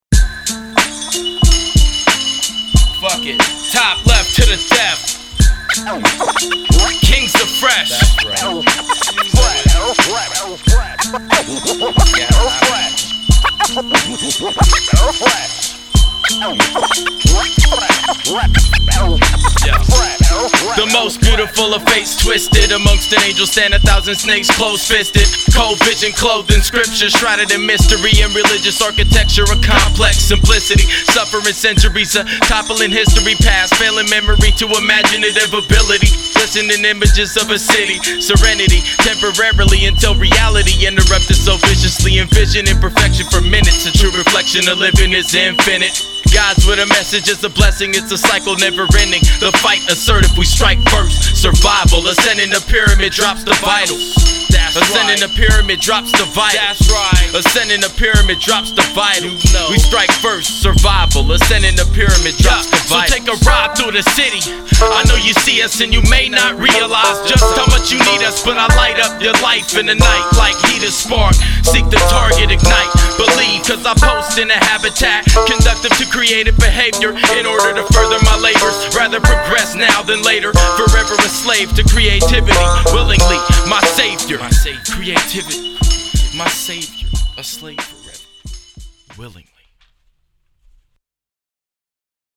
Recorded at Ground Zero Studios & Seattle ChopShop